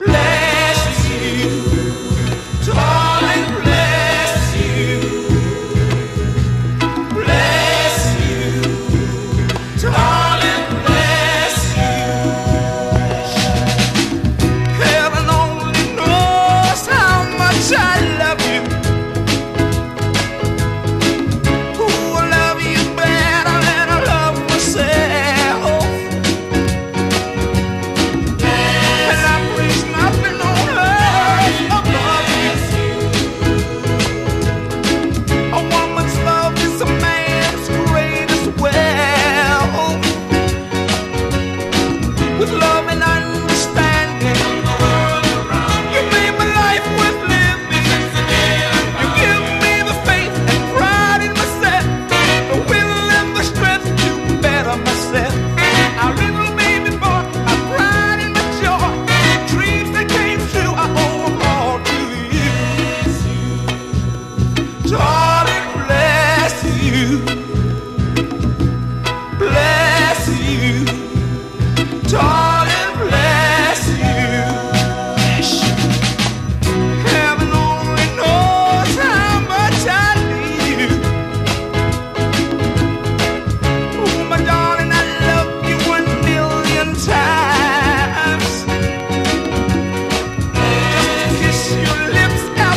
高揚感溢れるヤング・ノーザンソウル！